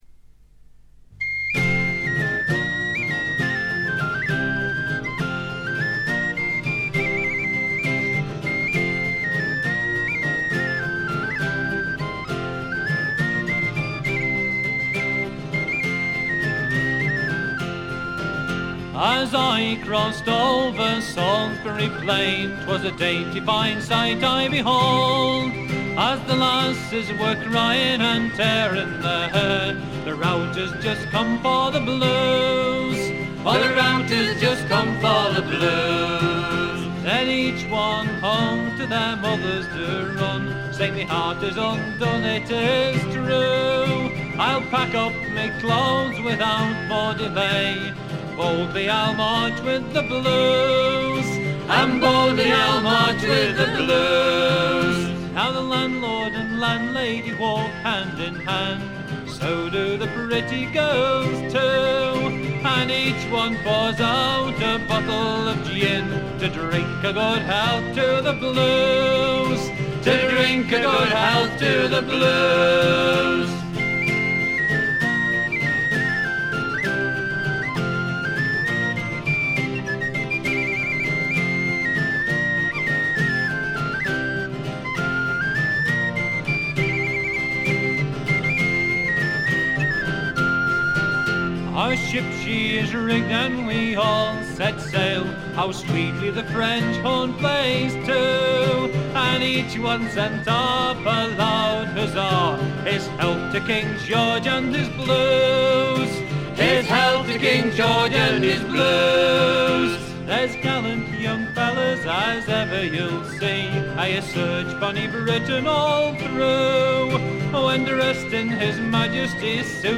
フィメールを含む4人組。
試聴曲は現品からの取り込み音源です。
Recorded at Mid Wales Sound Studio June 1976